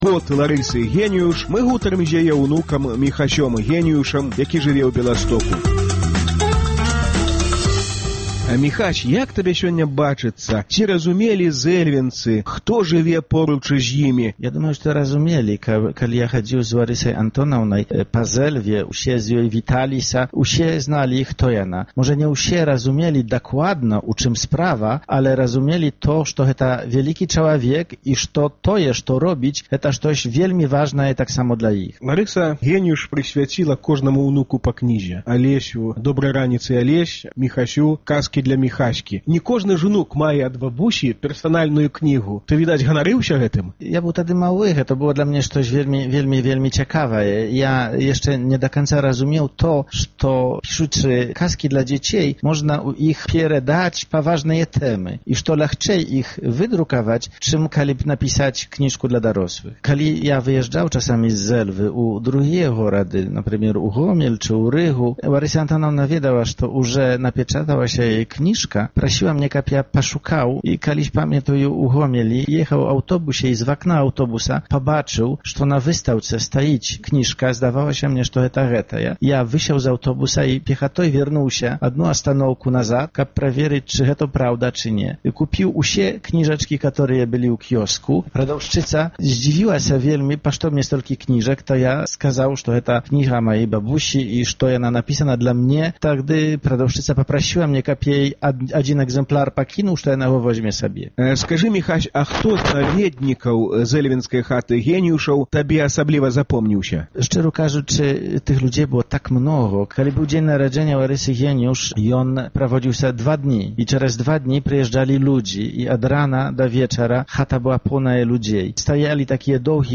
Зэльвенскую хату сваёй бабулі ён памятае да драбніцаў, як і яе жыцьцёвыя наказы… Гутарка